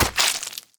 claw1.wav